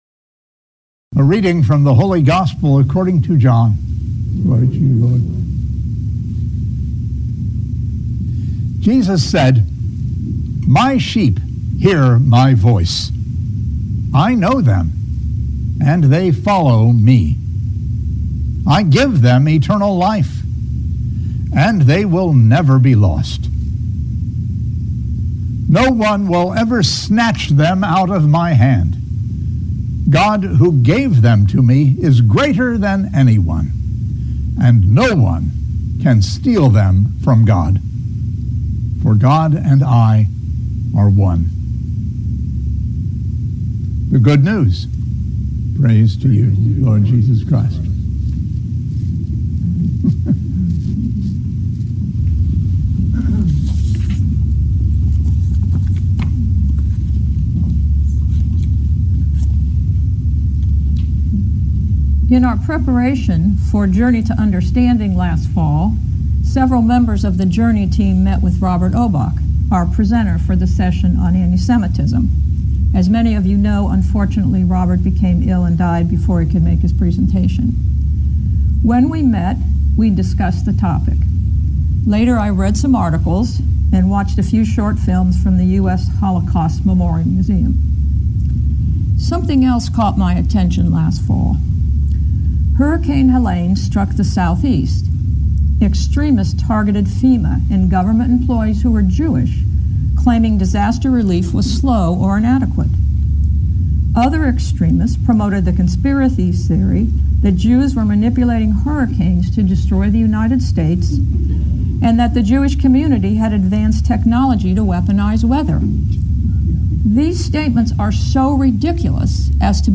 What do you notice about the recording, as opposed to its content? These homilies were offered during our 10a Sun weekly services. Each homily is preceded by the Gospel reading and followed by discussion. The discussion is not included in this podcast.